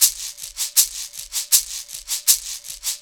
Shaker Loop A 159.wav